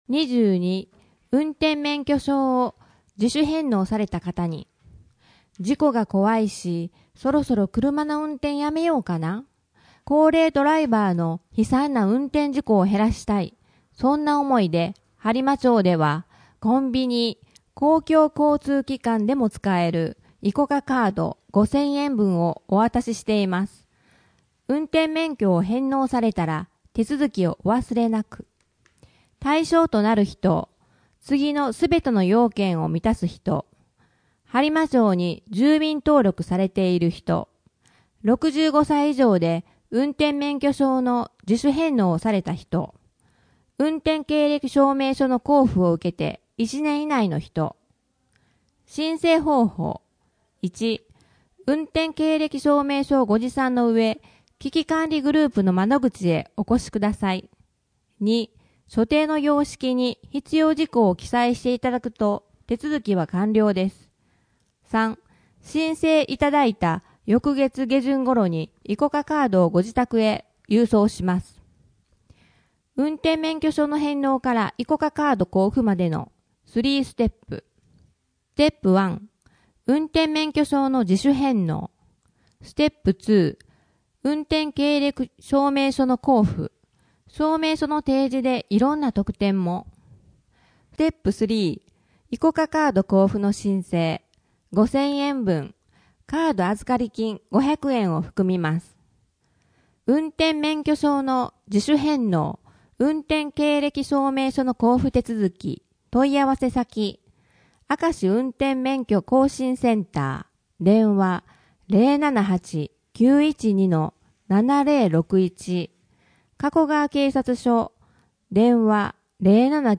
声の「広報はりま」はボランティアグループ「のぎく」のご協力により作成されています。